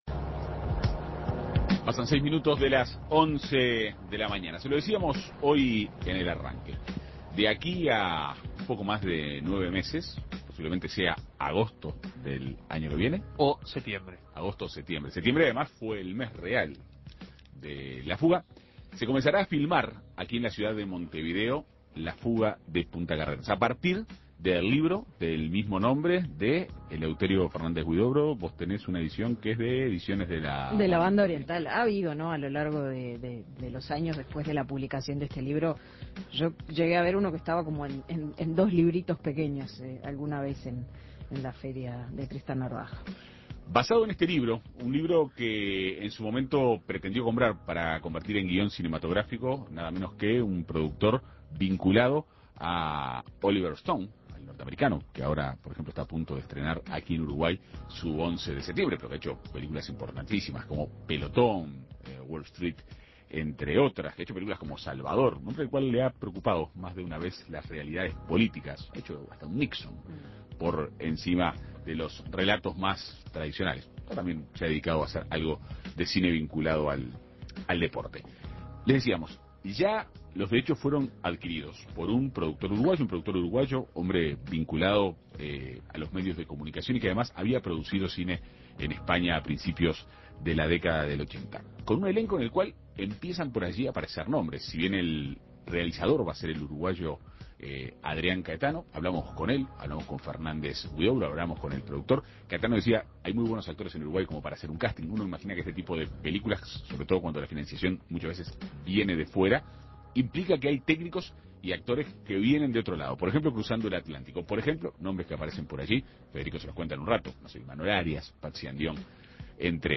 Entrevista con el director y con el senador Eleuterio Fernández Huidobro.